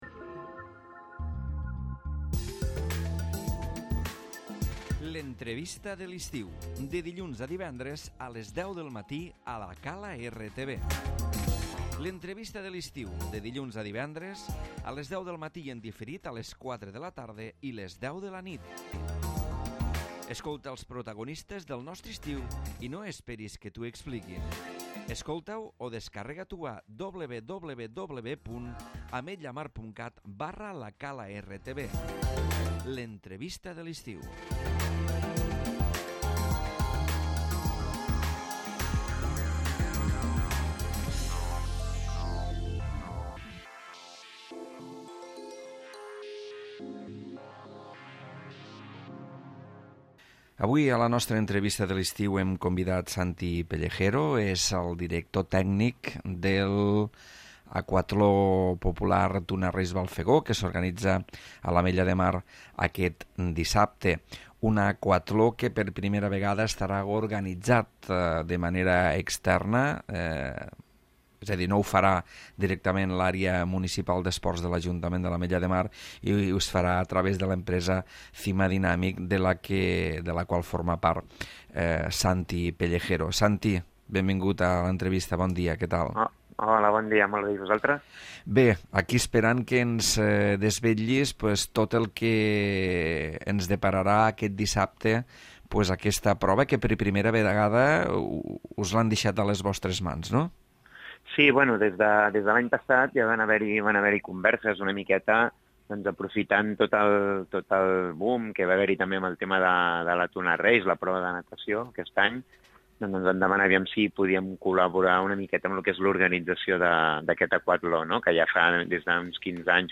Entrevista de l'Estiu